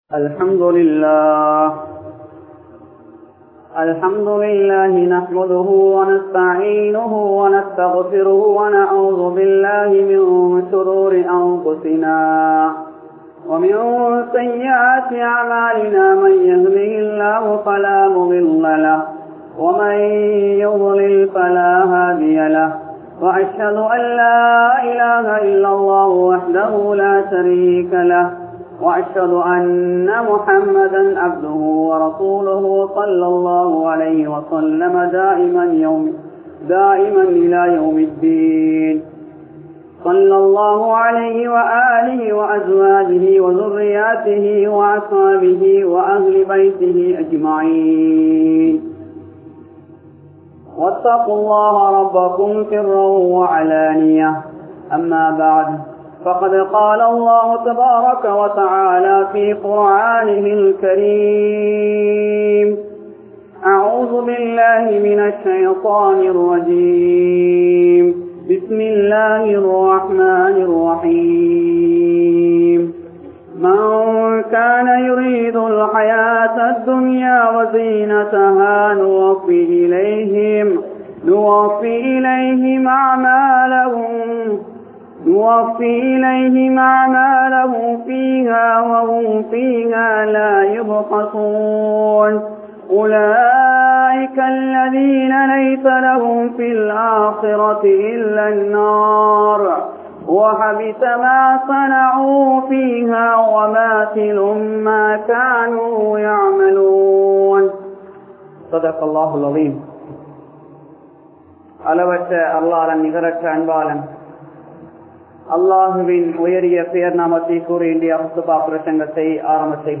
Olukkamulla Vaalifarhal (ஒழுக்கமுள்ள வாலிபர்கள்) | Audio Bayans | All Ceylon Muslim Youth Community | Addalaichenai
Yoosufiya Jumua Masjidh